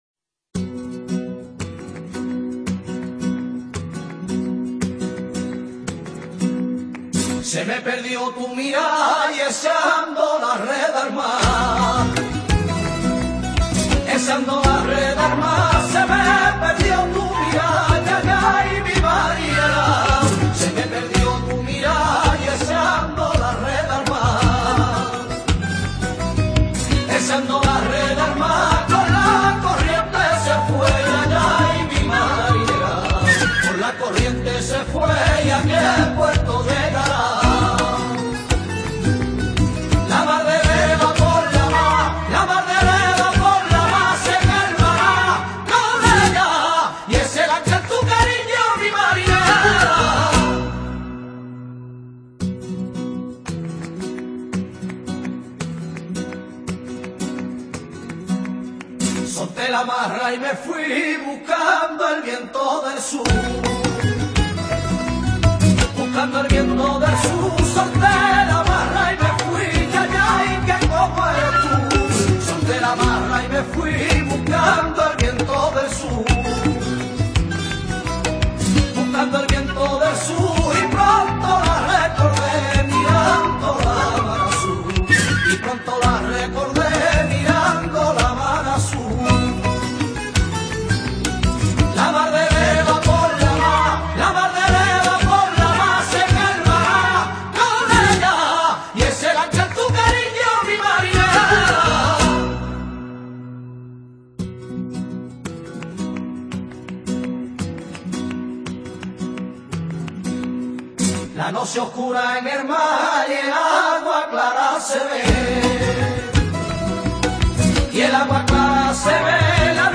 una entrega semanal de sevillanas para la historia.
Temática: Marineras